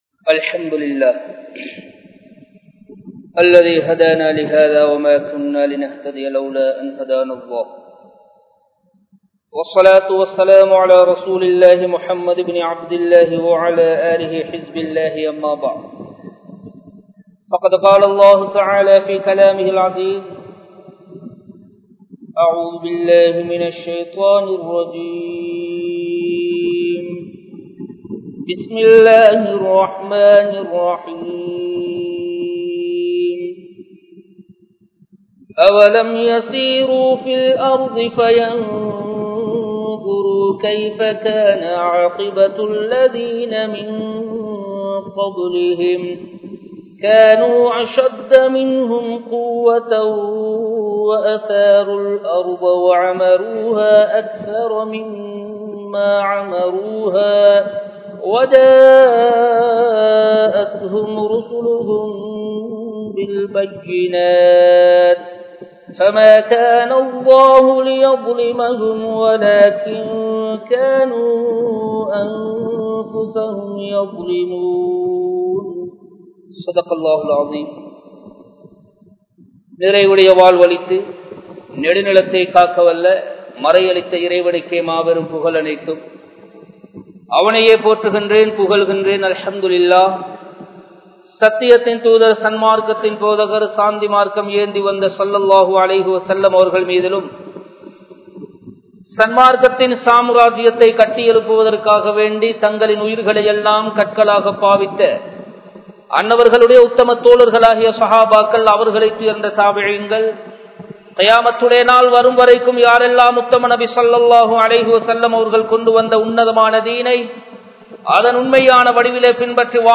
Vaalkaiyai Alikkum Paavangal (வாழ்க்கையை அழிக்கும் பாவங்கள்) | Audio Bayans | All Ceylon Muslim Youth Community | Addalaichenai
Colombo 12, Aluthkade, Muhiyadeen Jumua Masjidh